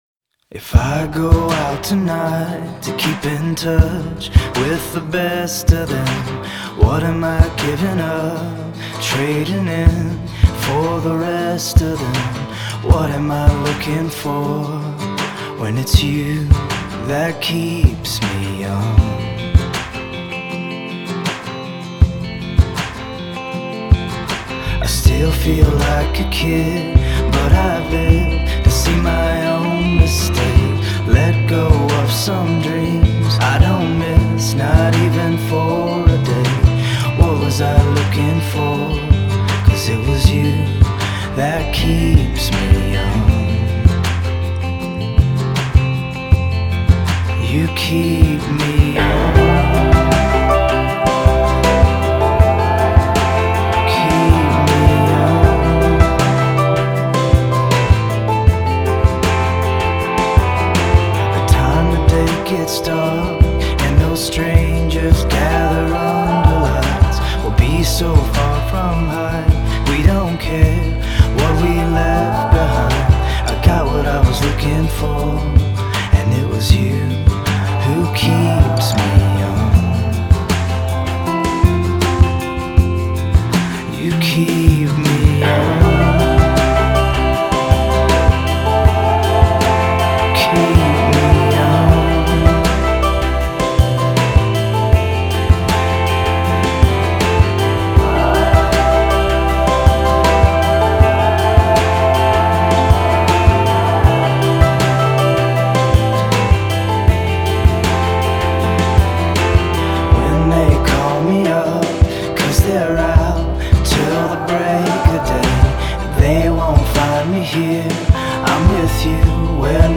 A shifting-sway of a rhythm